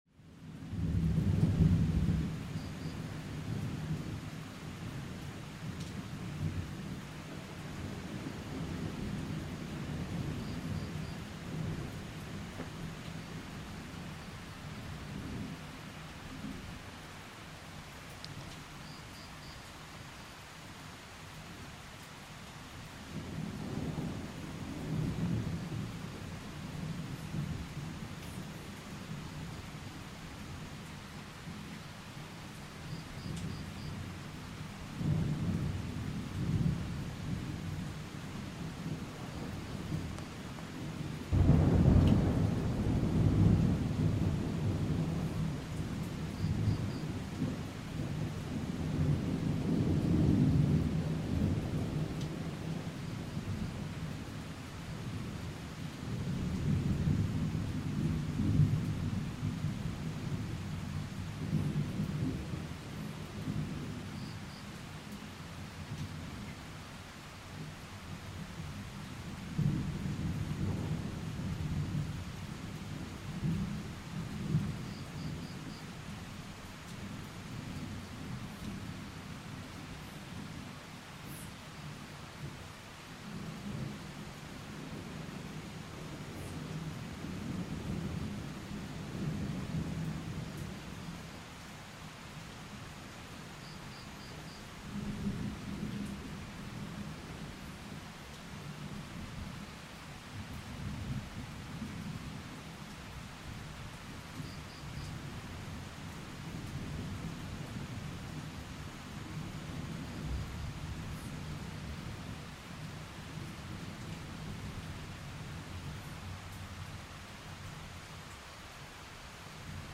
Thunderstorm in the Jungle
Jungle-Thunderstorm.mp3